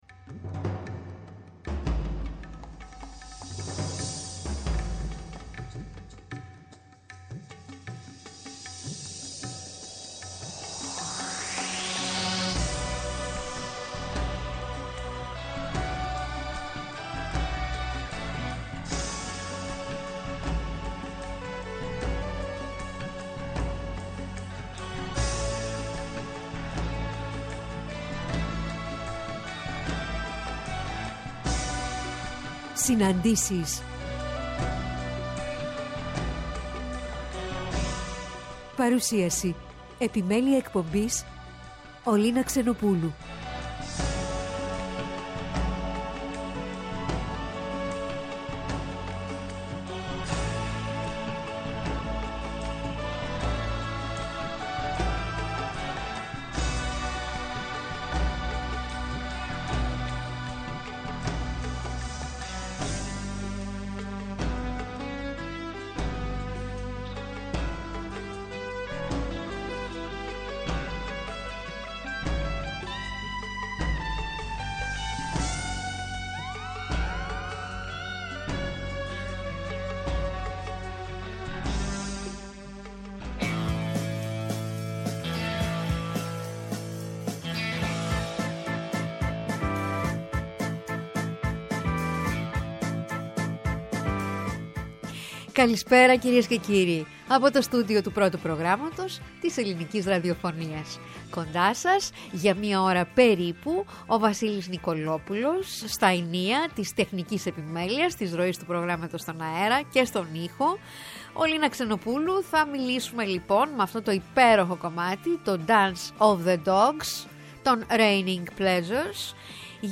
Στην εκπομπή «Συναντήσεις» στο Πρώτο πρόγραμμα την Κυριακή 30-04-23 «Παγκόσμια Ημέρα Τζαζ» και ώρα 16:00-17:00: